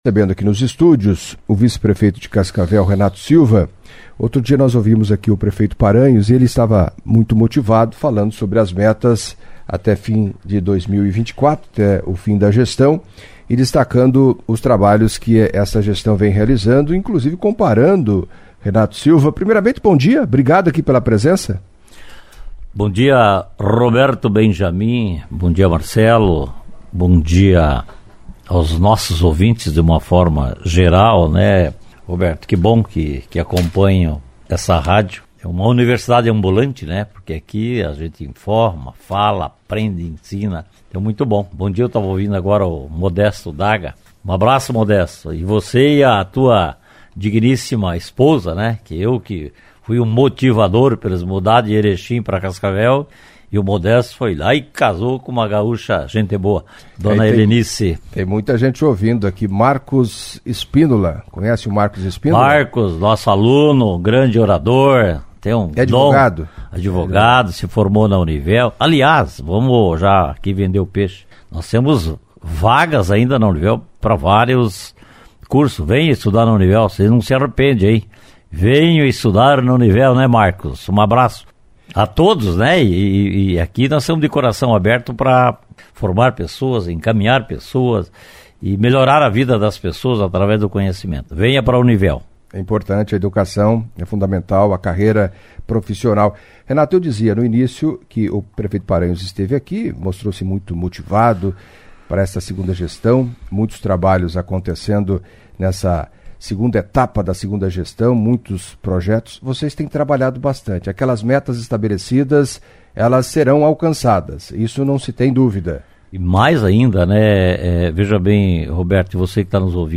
Em entrevista à CBN nesta quinta-feira (23) o vice-prefeito de Cascavel, Renato Silva, falou da movimentação nos bastidores da política e da possibilidade de disputar a cadeira, atualmente, ocupada pelo prefeiro Paranhos, em 2024.